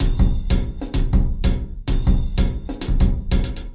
描述：奇数时间节拍120bpm
Tag: 回路 常规 时间 节奏 120BPM 节拍 敲击循环 量化 鼓环 有节奏